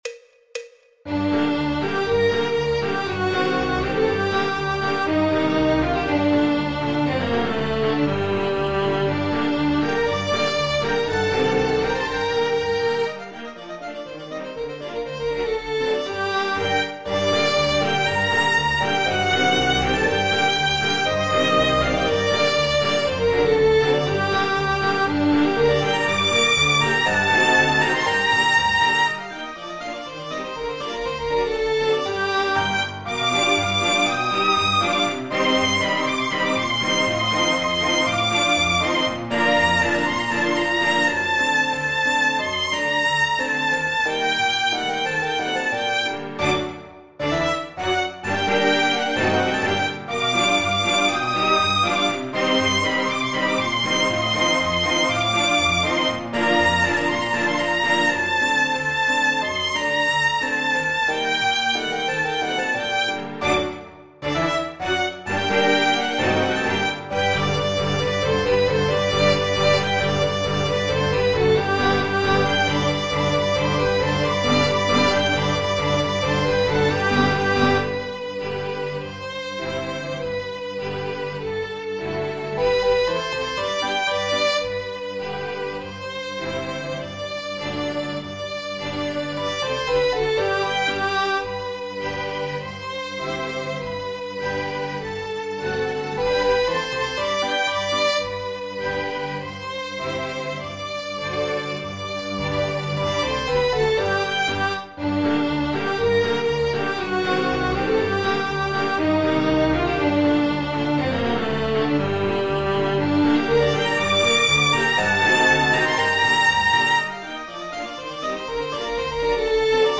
El MIDI tiene la base instrumental de acompañamiento.
Popular/Tradicional